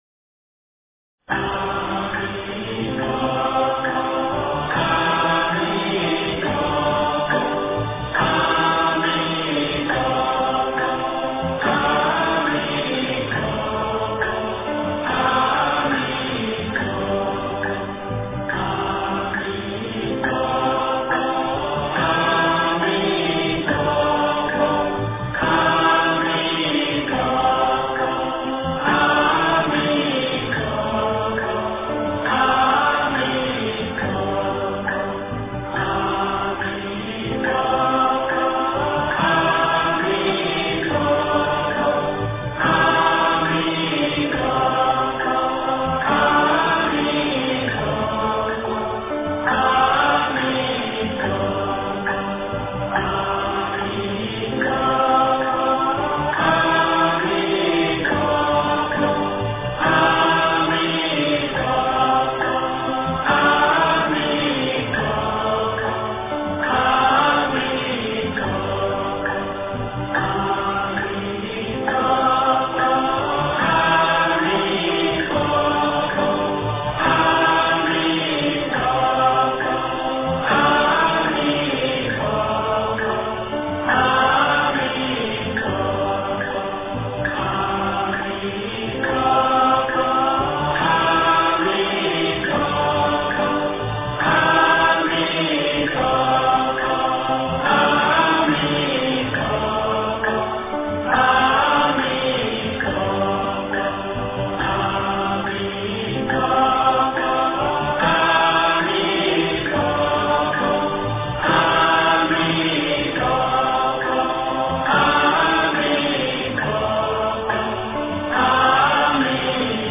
经忏
佛音 经忏 佛教音乐 返回列表 上一篇： 早课--辽宁海城大悲寺 下一篇： 楞严咒 大悲咒 十小咒--中台禅寺 相关文章 晚课-佛说阿弥陀经 往生咒 赞佛偈--慧律法师率众 晚课-佛说阿弥陀经 往生咒 赞佛偈--慧律法师率众...